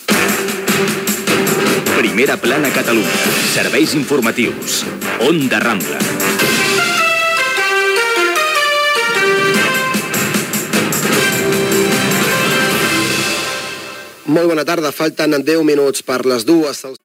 Careta del programa i hora
Informatiu